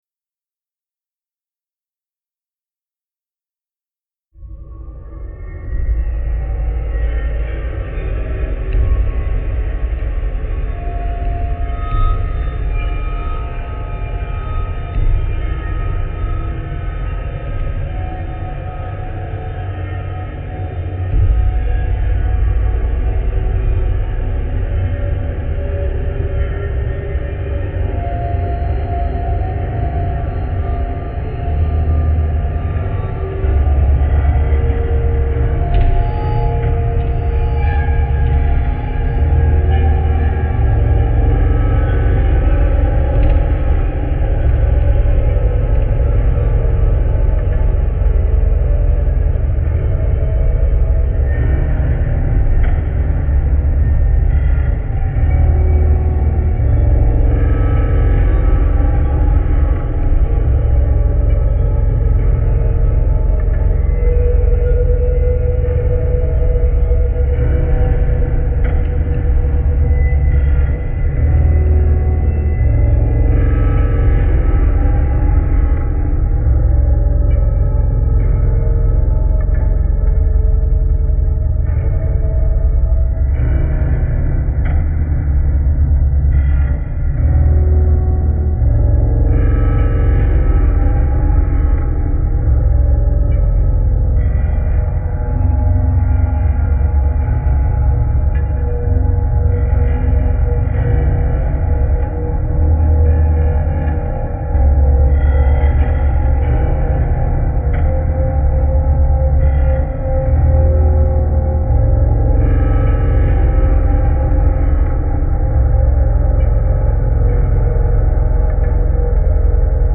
Genre: Dark Ambient.